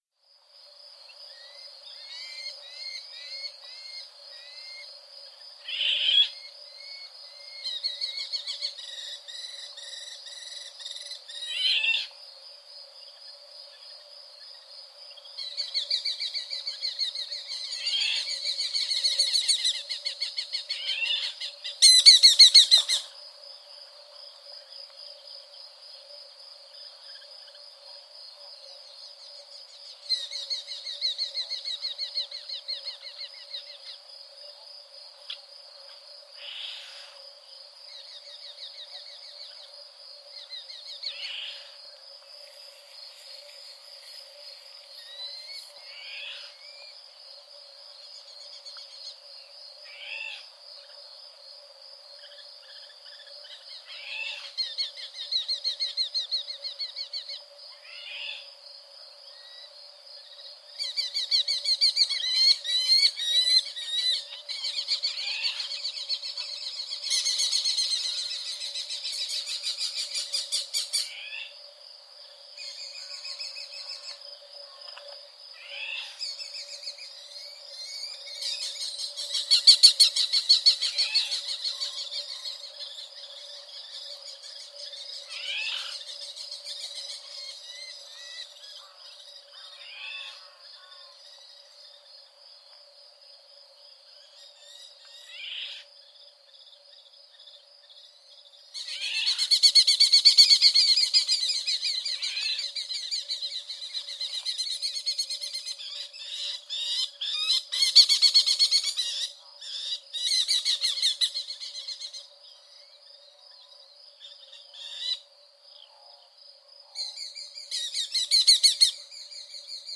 Вы услышите разнообразные голосовые вариации: от характерных резких криков до более мягких перекличек. Записи сделаны в дикой природе разных регионов, что позволяет оценить особенности вокализации птицы.
Послушайте голос кобчика из Казахстана